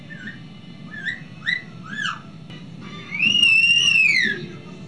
I can't carry a tune very well, but I can say "HELLO" the best, then
WHISTLE.WAV